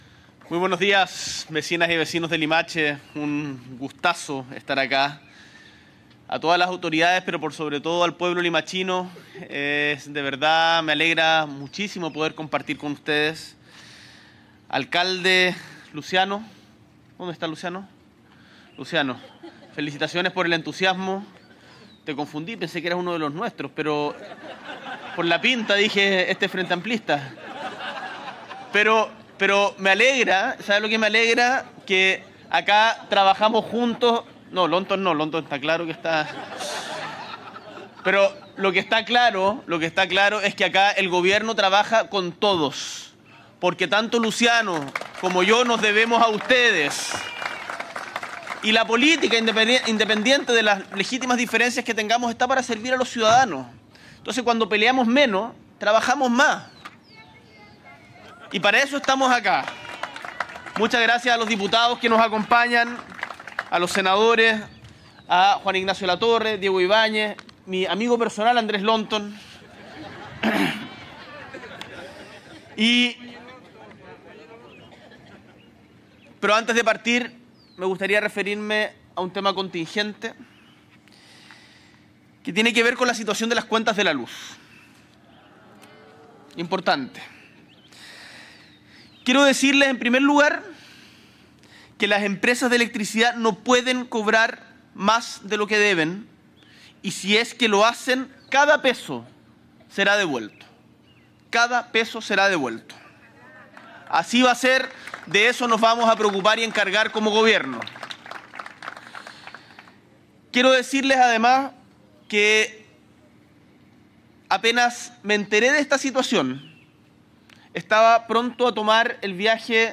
Presidente de la República, Gabriel Boric Font, inaugura nuevo cuartel de la PDI en Limache
Con una inversión total de más de 3.129 millones de pesos, el nuevo edificio cuenta con dos niveles, un área de toma de denuncias y entrevistas, un área de atención de público, calabozos, entre otras instalaciones. En la ceremonia, además, el Jefe de Estado recibió la Política Nacional de Seguridad Pública, hoja de ruta de un nuevo modelo de gestión en seguridad y la primera política nacional de seguridad pública en 21 años.